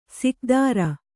♪ sikdāra